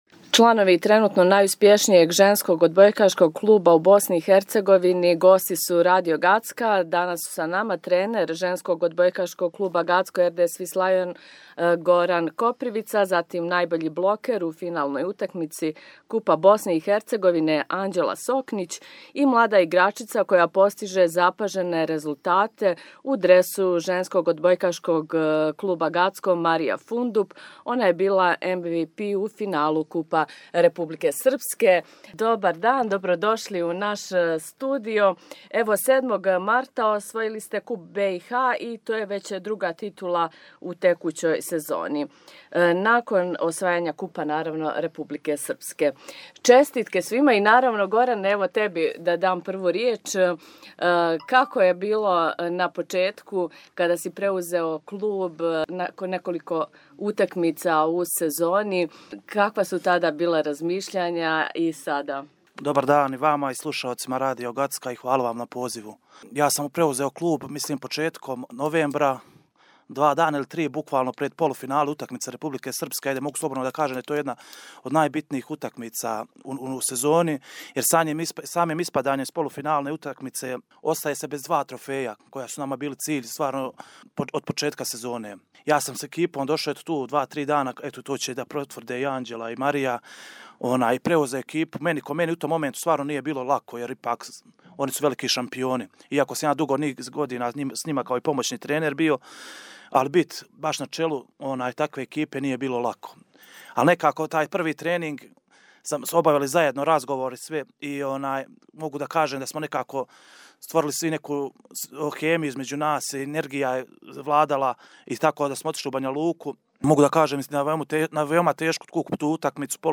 Чланови тренутно најуспјешнијег женског одбојкашког клуба у БиХ, ЖОК „Гацко РД Свислајон“ Гацко, били су гости Радио Гацка након двије одбрањене титуле.